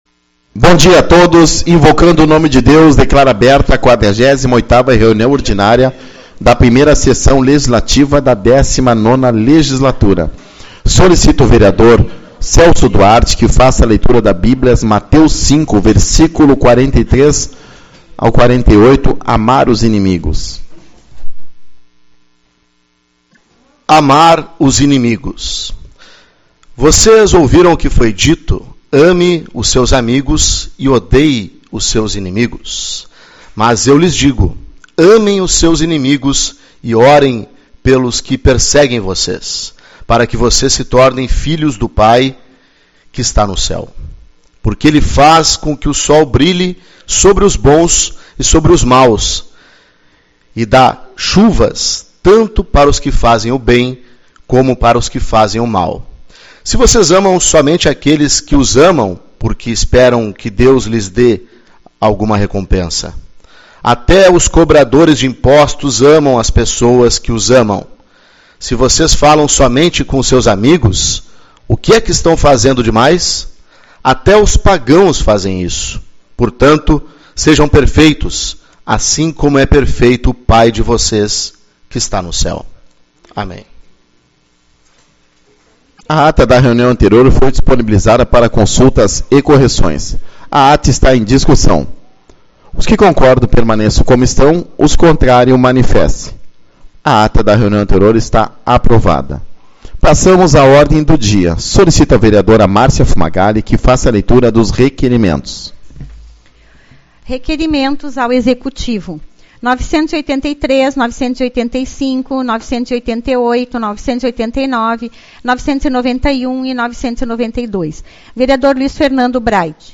12/08 - Reunião Ordinária